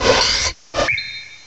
Add all new cries
cry_not_gallade.aif